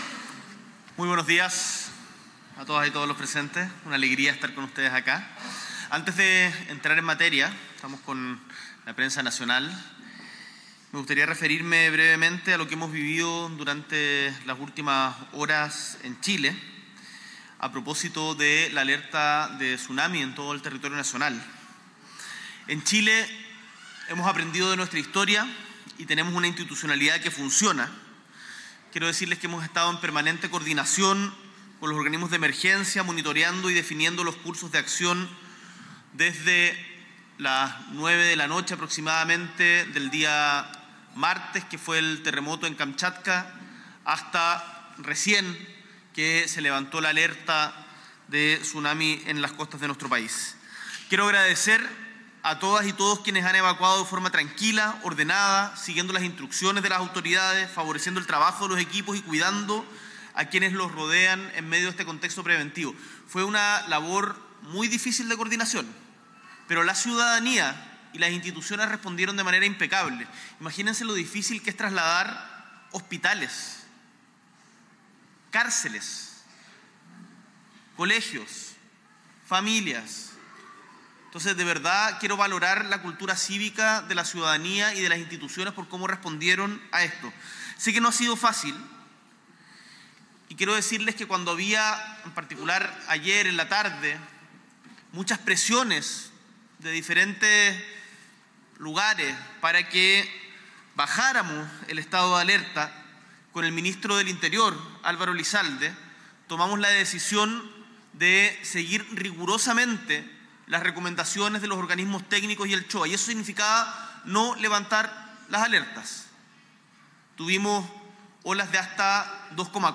S.E. el Presidente de la República, Gabriel Boric Font, encabeza la promulgación de la nueva Ley de Adopción
Discurso